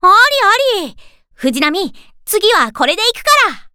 Ship Voice Fujinami Kai Ni Equipment 1.mp3
Ship_Voice_Fujinami_Kai_Ni_Equipment_1.mp3